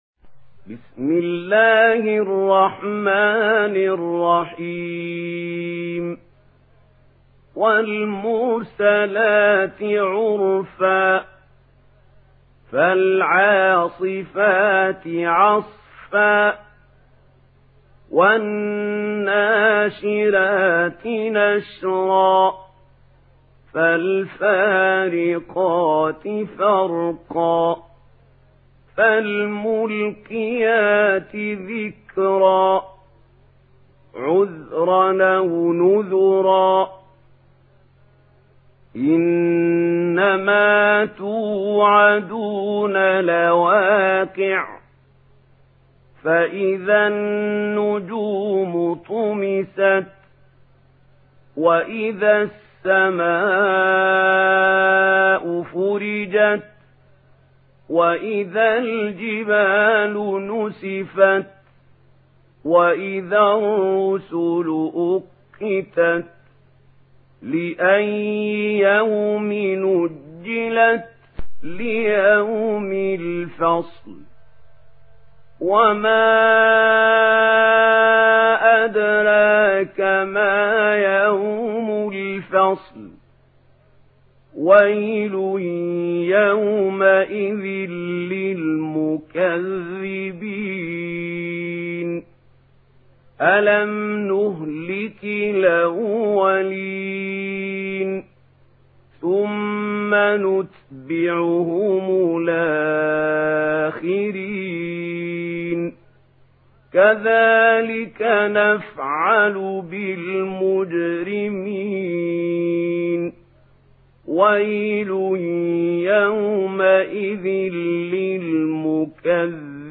Murattal Warsh An Nafi